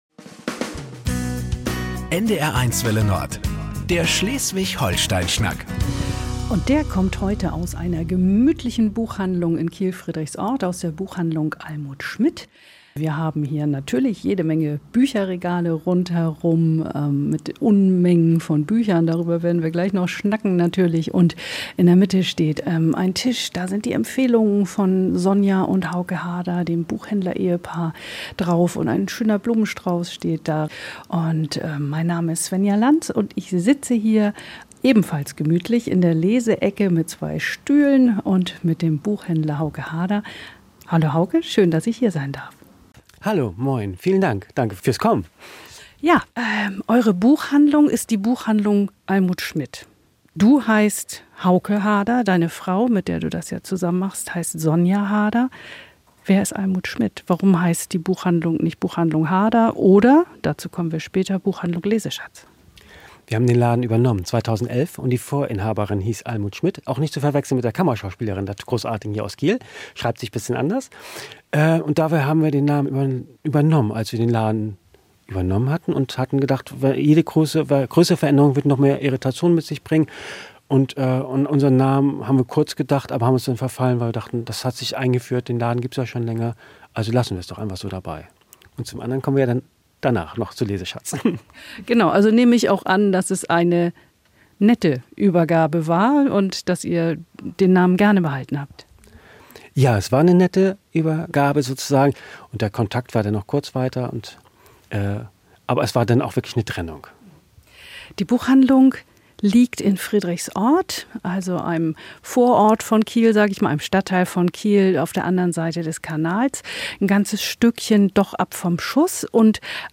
Jeden zweiten Dienstag von 20 bis 21 Uhr reden wir mit Menschen, die uns etwas zu erzählen haben.